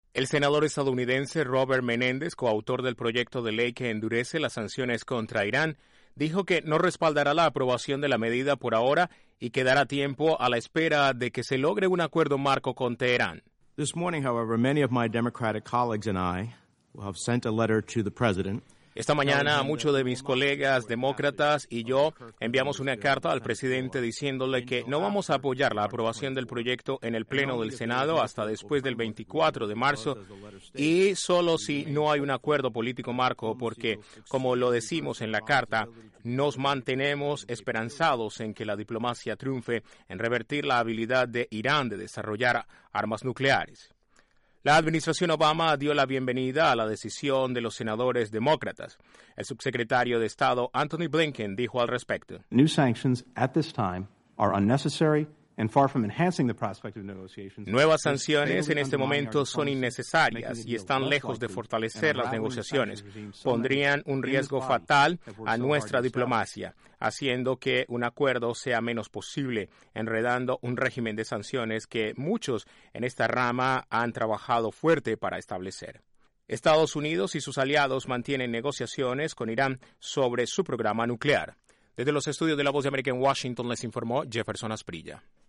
Un grupo de senadores demócratas enviaron una carta a la Casa Blanca en la que dicen no apoyarán nuevas sanciones a Irán hasta por lo menos fines de marzo a la espera de un posible acuerdo. Desde la Voz de América en Washington informa